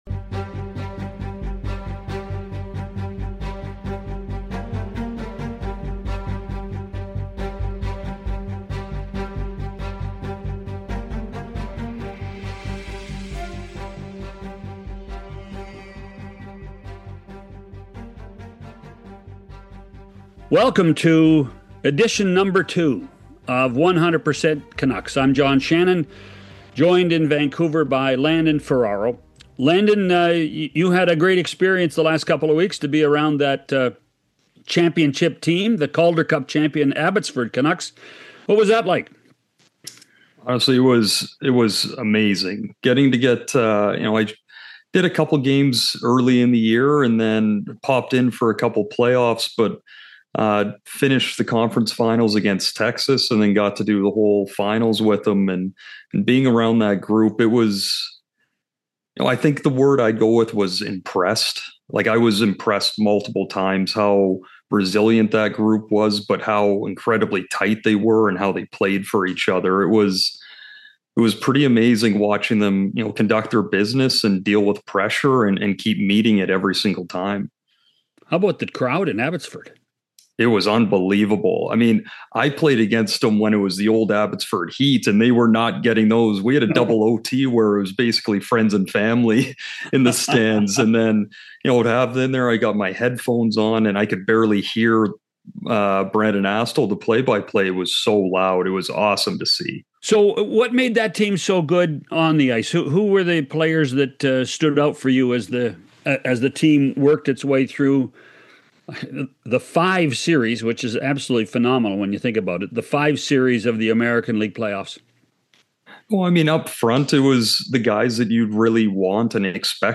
Adam Foote joins the show to give us a clear look at how the Vancouver Canucks will take shape under his coaching approach. From his influence behind the bench to his philosophy on team identity, Adam outlines the style of hockey he wants to see and what fans can expect from the Canucks next season. It’s a revealing conversation with one of the newest voices guiding the team forward.